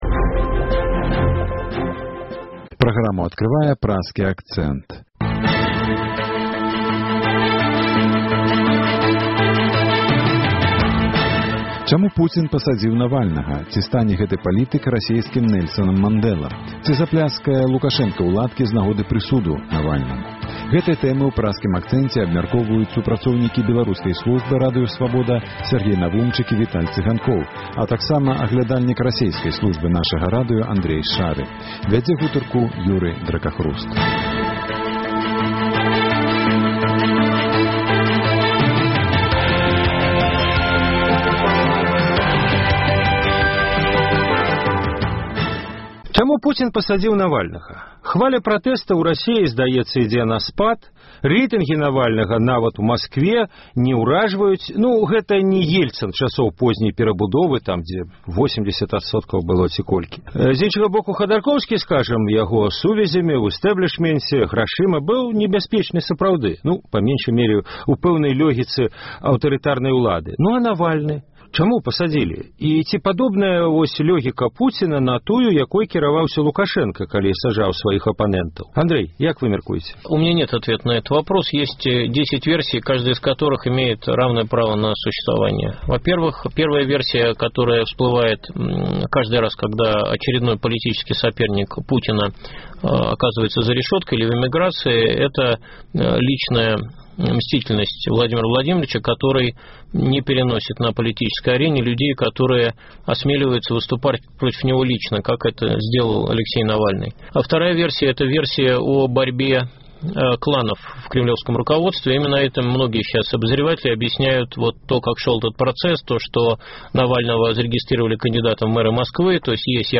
Ці стане гэты палітык расейскім Нэльсанам Мандэлам? Ці пляскае Лукашэнка ў ладкі з нагоды прысуду Навальнаму? Гэтыя тэмы ў Праскім акцэнце абмяркоўваюць